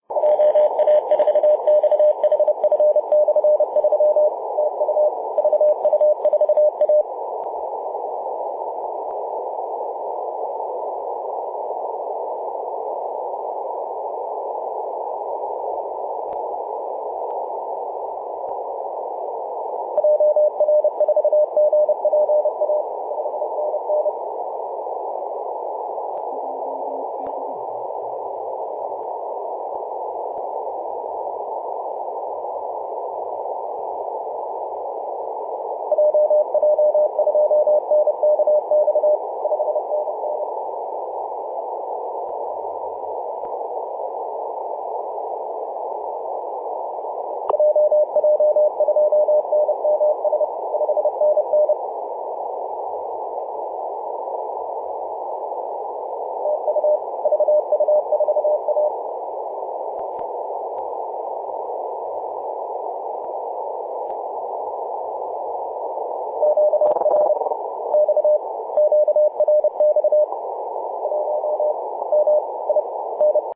Mar/08 0617z VU4A 21.012MHz CW